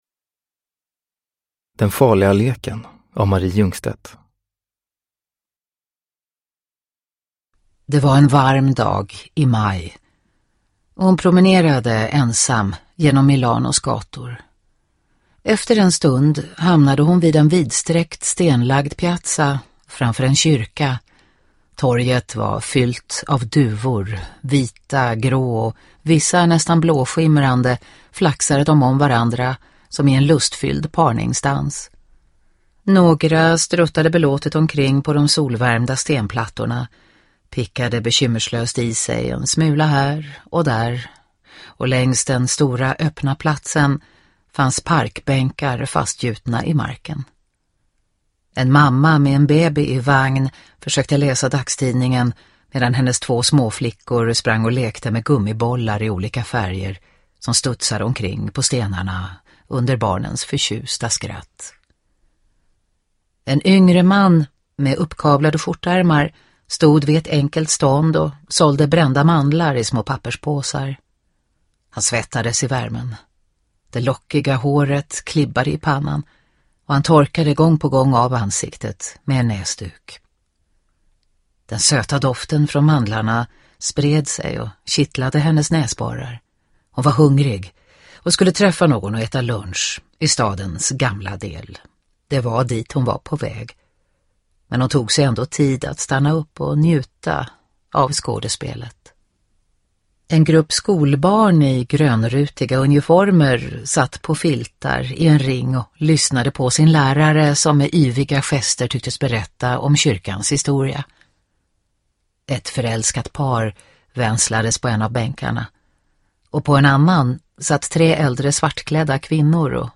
Den farliga leken (ljudbok) av Mari Jungstedt